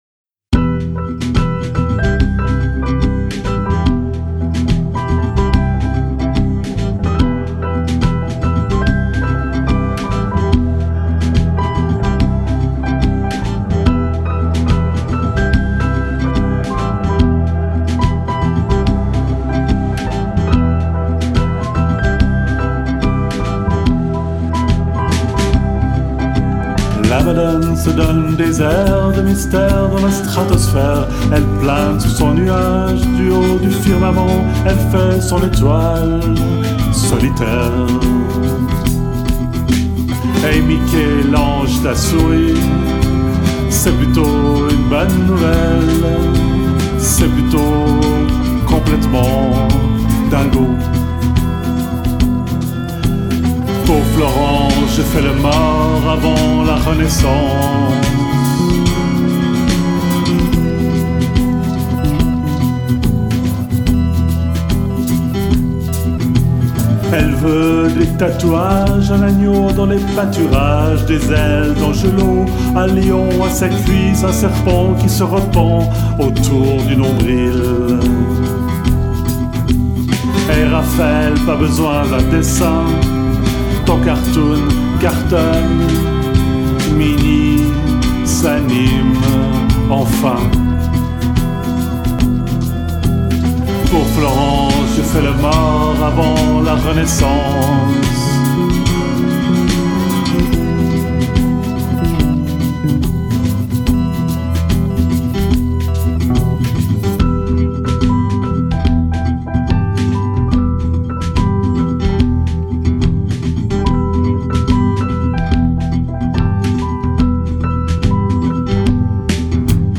guitare, basse, clavier
Studio des Anges, Lausanne